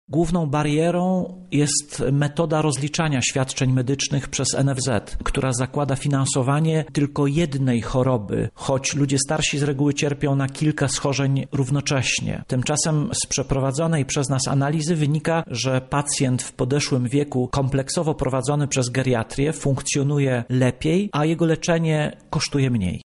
O szczegółach mówi